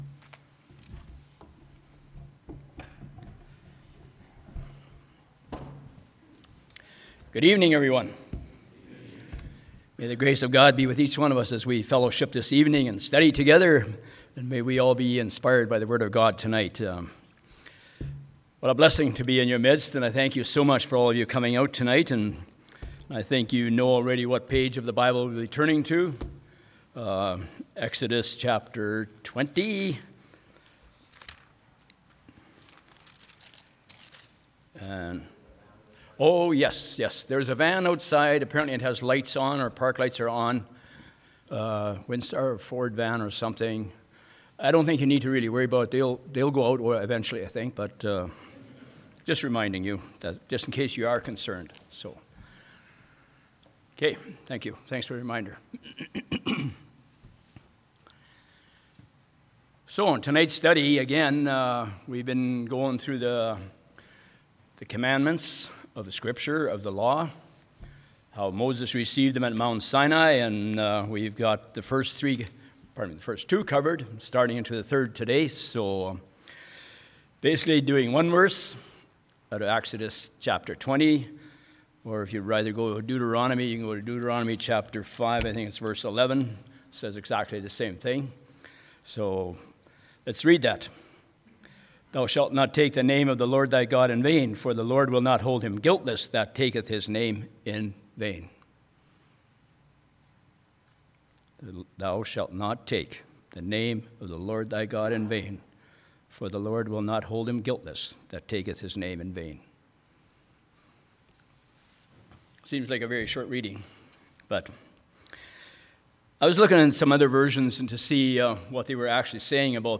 Church Bible Study